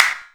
CLAP193.wav